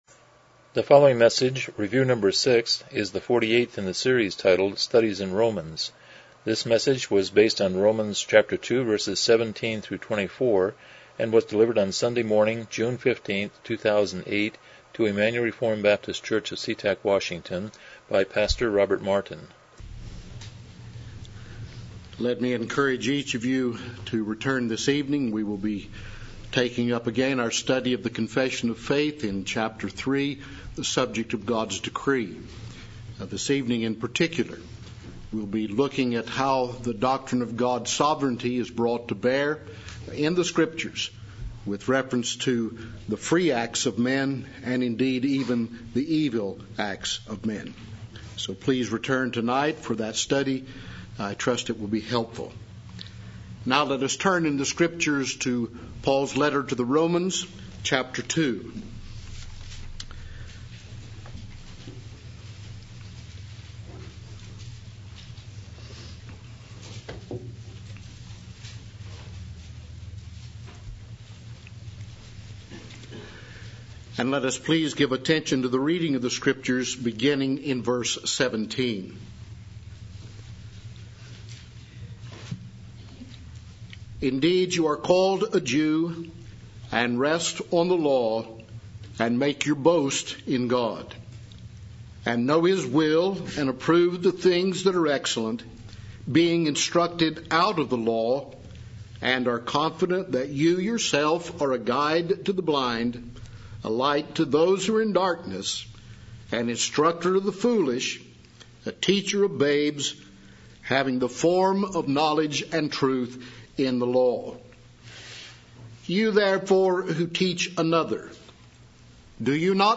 Series: Exposition of Romans Passage: Romans 2:17-24 Service Type: Morning Worship